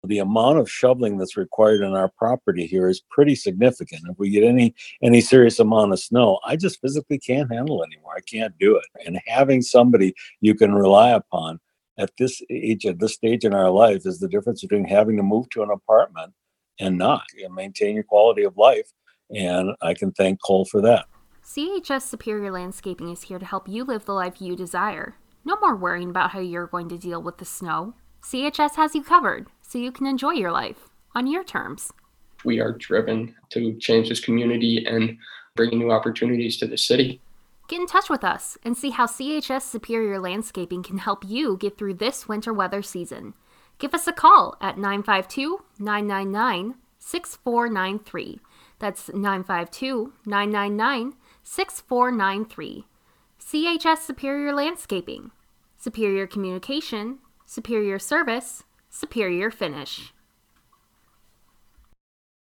Radio Ad for CHS Landscaping